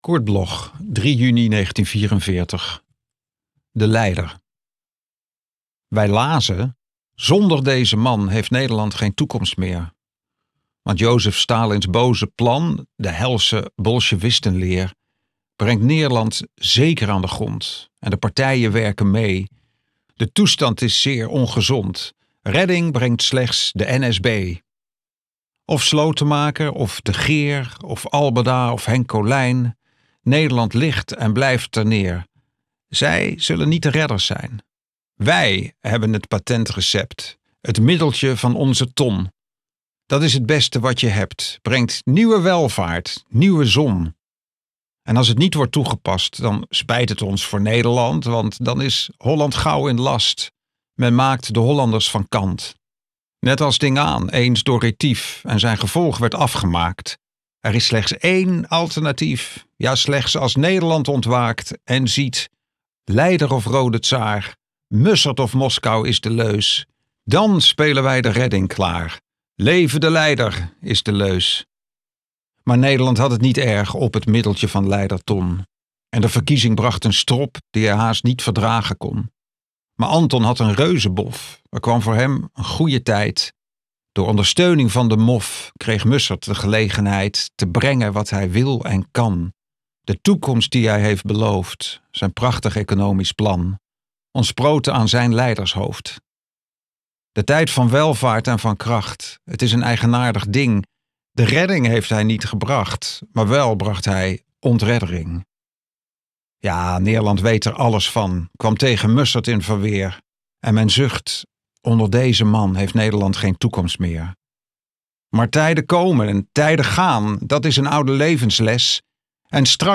Aufnahme: MOST, Amsterdam · Bearbeitung: Kristen & Schmidt, Wiesbaden